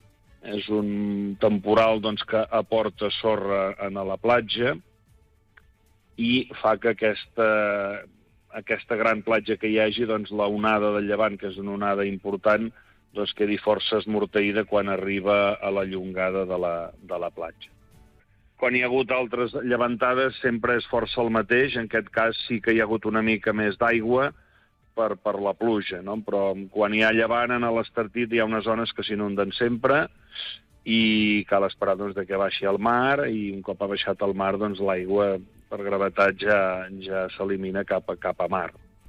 En una entrevista al Supermatí, Genís Dalmau, president de l’Entitat Municipal Descentralitzada (EMD) de l’Estartit, ha fet balanç de les afectacions del temporal Harry, que ha colpejat amb força la comarca del Baix Empordà.